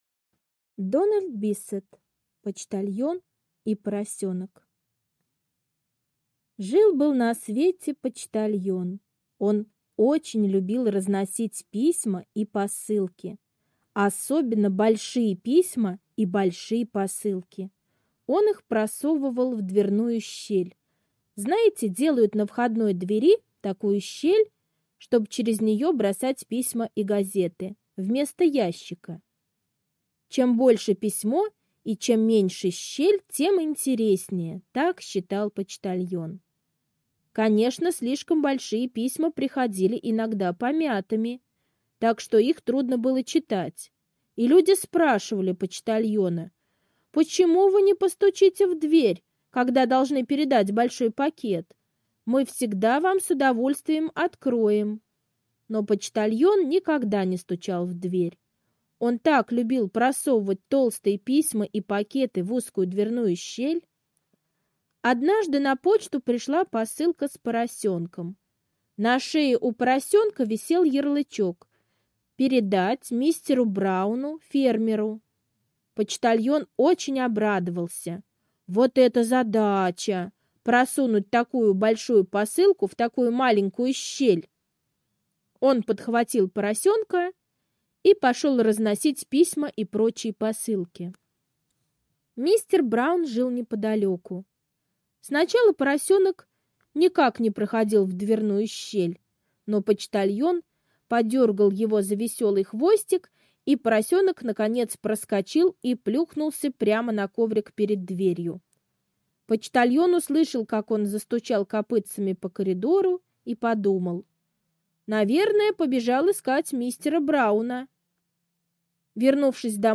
Почтальон и поросенок - аудиосказка Биссета Д. Сказка про ответственного почтальона, который доставил необычную посылку.